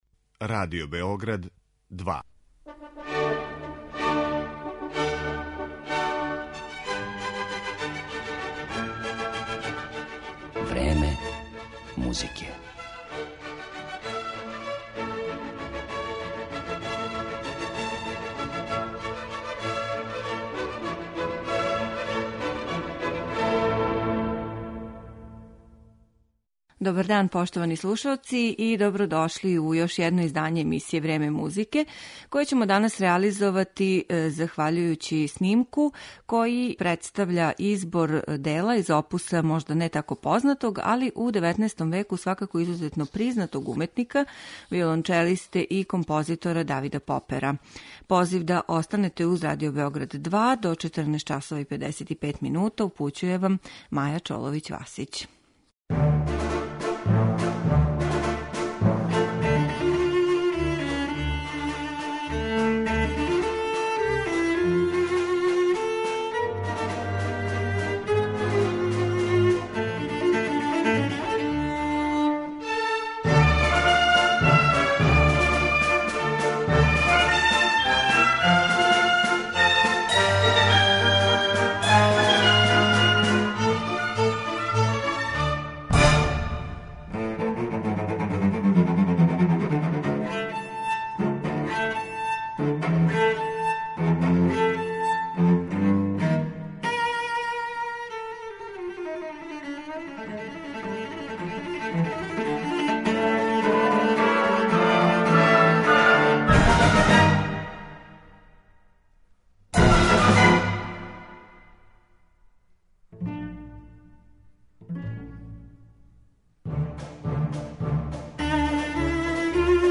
Музика Давида Попера за виолончело